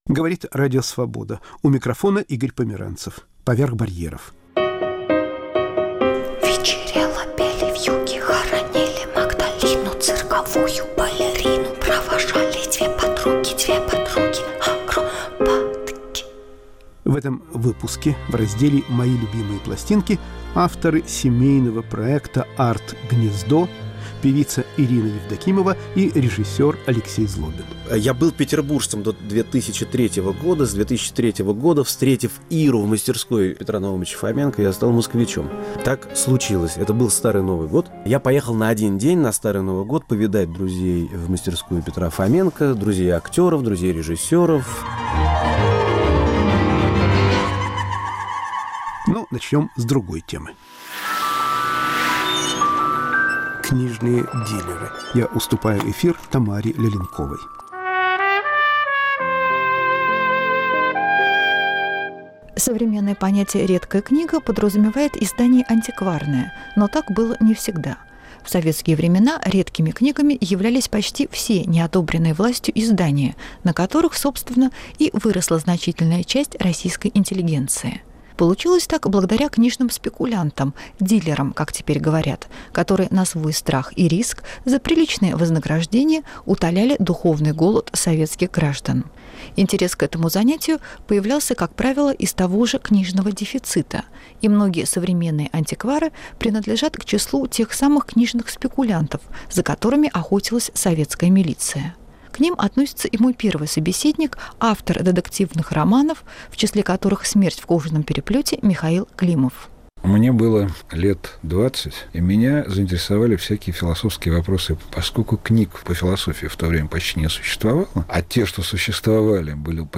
Книжные дилеры о своей профессии *** Народная актриса: рассказывает и поёт деревенская учительница